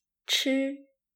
chī
チー
カタカナ表記では「チー」ですが、実際の発音は「チ」と「ツ」の間のような音で少しこもった音になります。